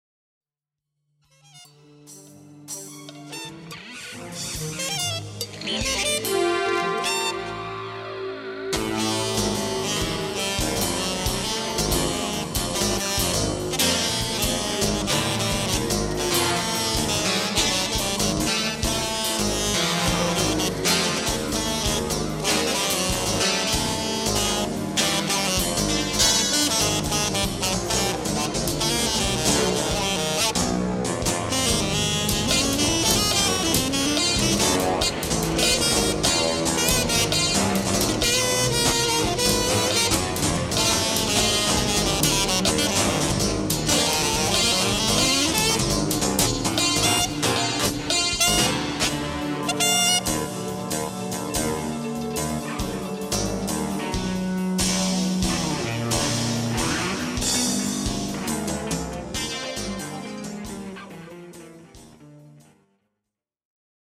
bass
sax and flutes
drums and keyboards